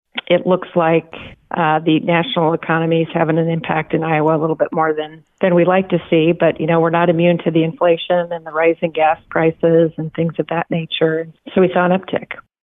Iowa Workforce Development executive director, Beth Townsend, says conditions on the national level factor in.